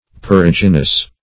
Search Result for " perigynous" : The Collaborative International Dictionary of English v.0.48: Perigynous \Pe*rig"y*nous\, a. [Pref. peri- + Gr.